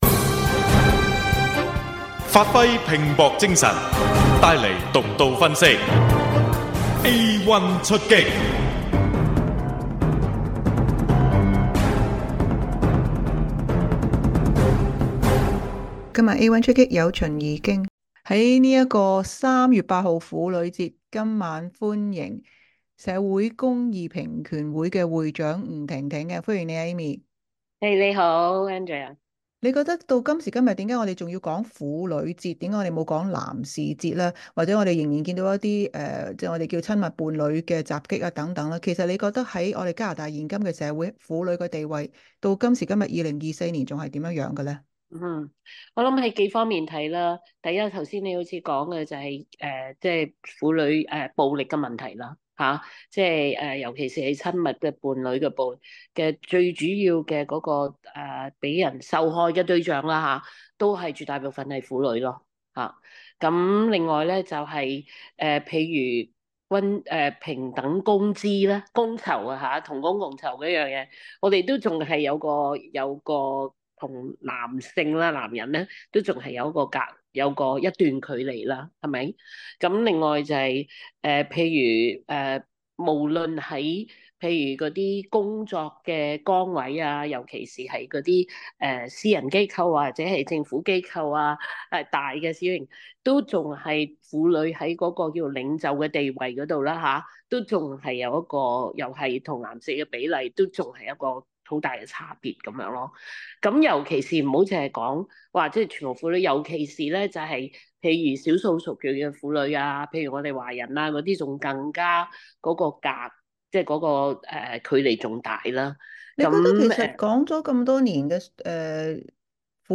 【有声访问】三八妇女节  今时今日加拿大还要强调女权吗？